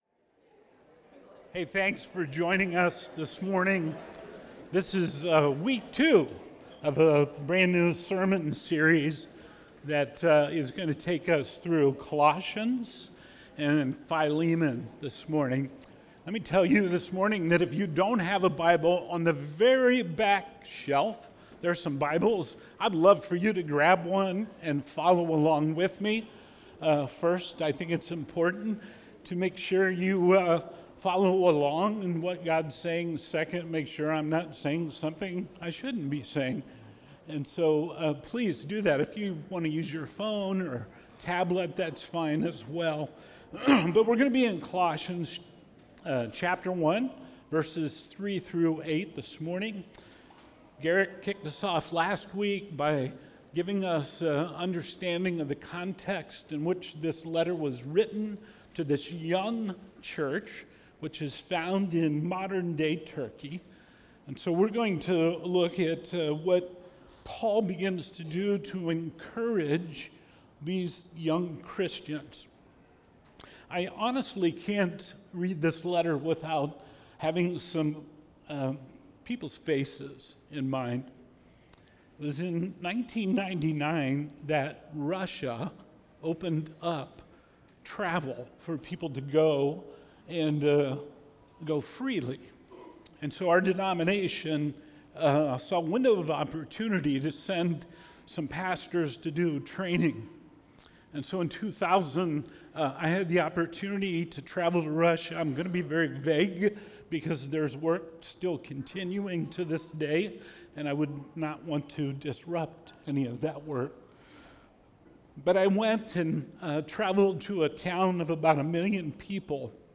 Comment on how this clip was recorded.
Sunday Worship | Substance Church, Ashland, Ohio